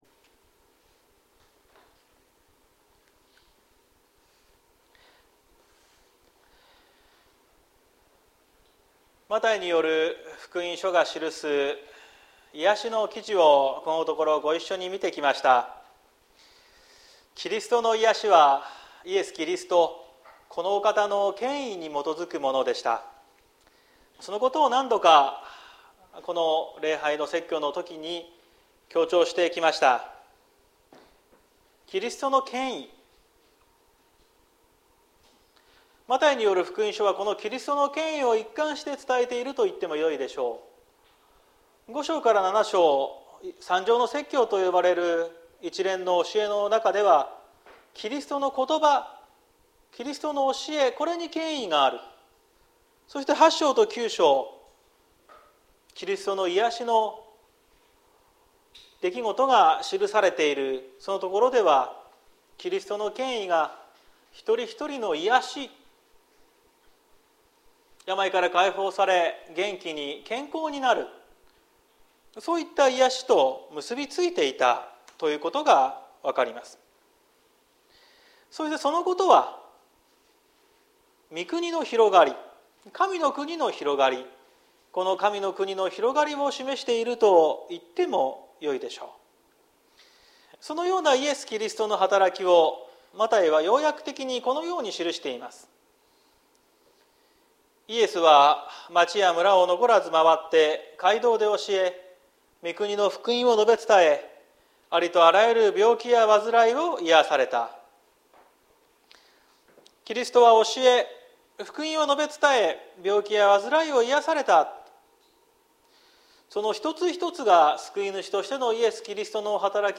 2023年09月17日朝の礼拝「収穫の主に願う」綱島教会
説教アーカイブ。